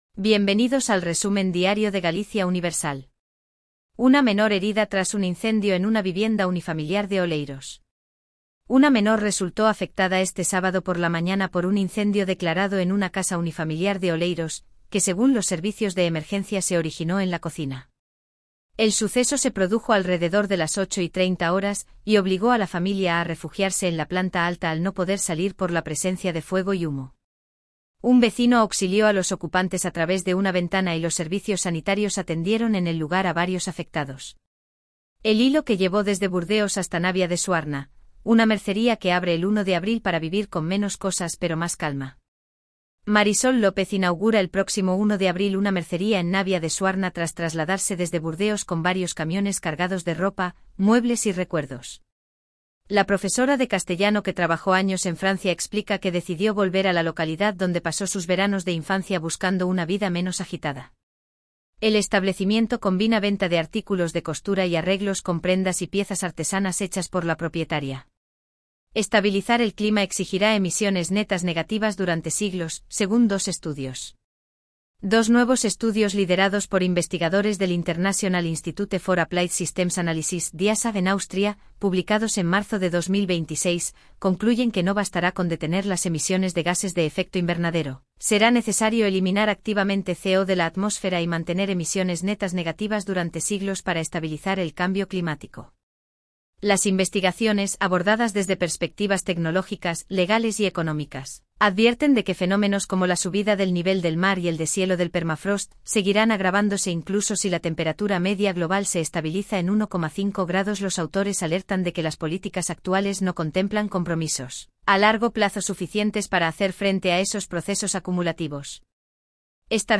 Voz: Elvira · Generado automáticamente · 5 noticias